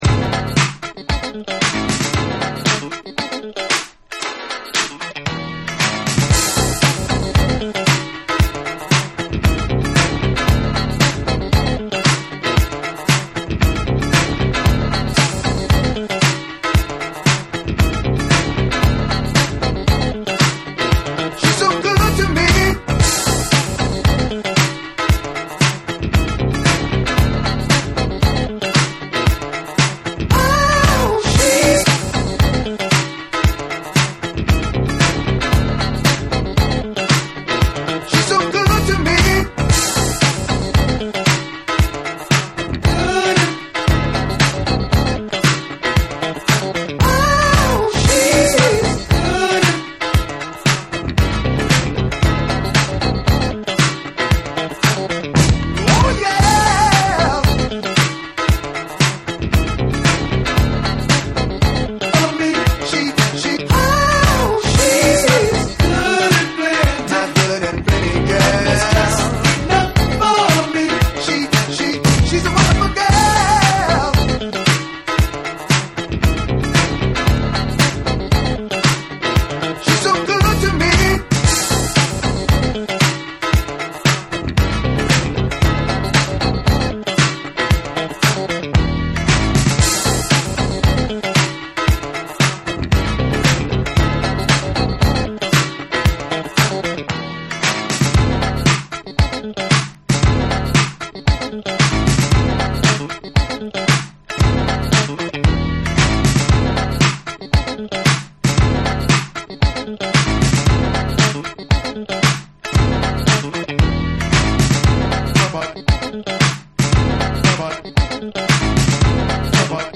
DANCE CLASSICS / DISCO / RE-EDIT / MASH UP